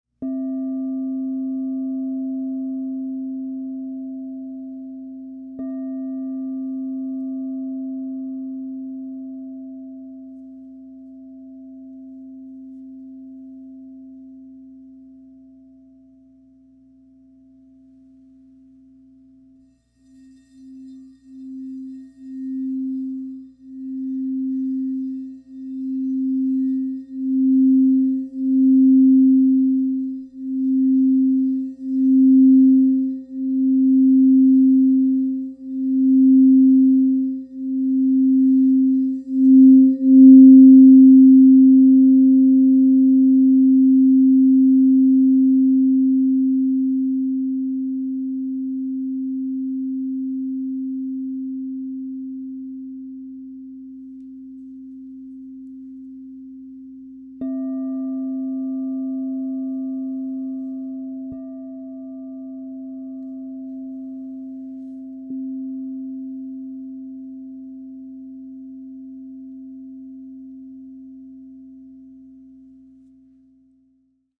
Abalone, Platinum 8″ C -10 Crystal Tones Singing Bowl